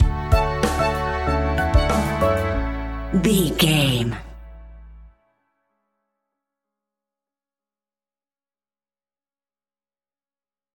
Happy Days Pop Rock Stinger.
Ionian/Major
pop rock
indie pop
fun
energetic
uplifting
instrumentals
upbeat
groovy
guitars
bass
drums
piano
organ